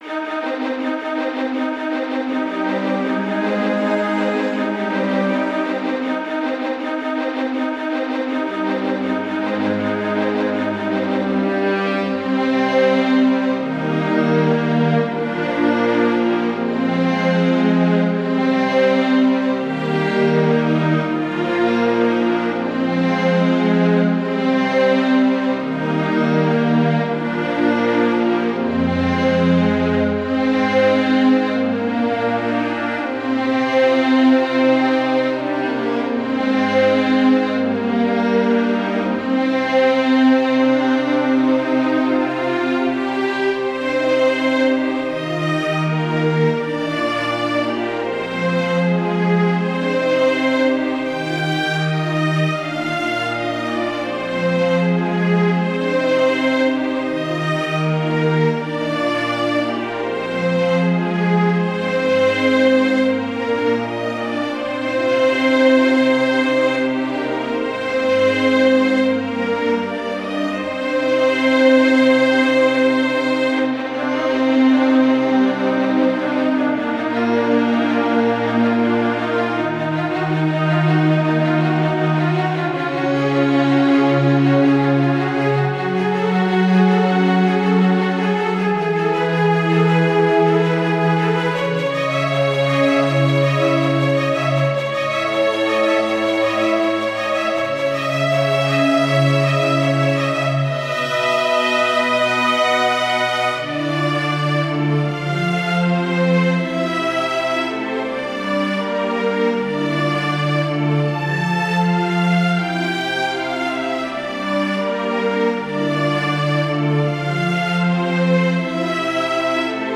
para Quarteto de Cordas
● Violino I
● Violino II
● Viola
● Violoncelo